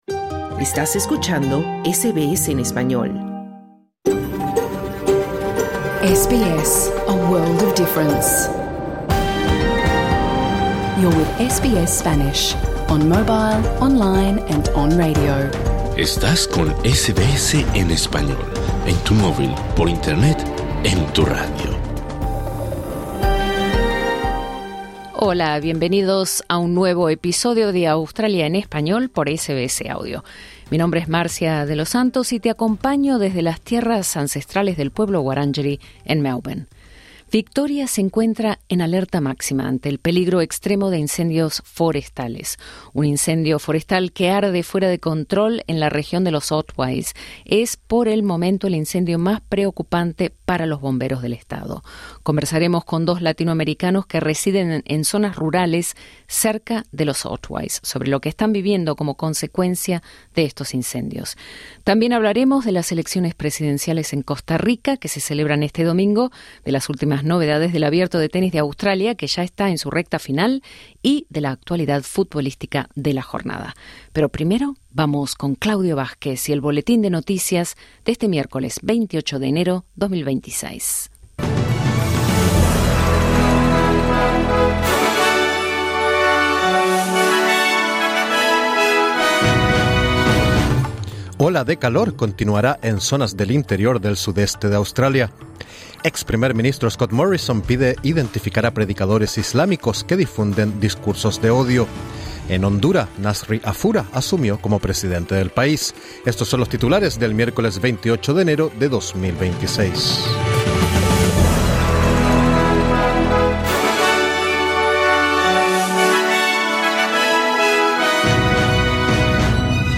Programa 28/01/26: Victoria se encuentra en alerta máxima ante el peligro extremo de incendios forestales. Dos latinoamericanos que viven en la región de los Otways, una de las zonas más castigadas por los incendios forestales, comparten su experiencia.